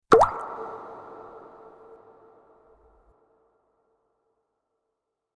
Water-drop-sound-effect-with-a-large-echo.mp3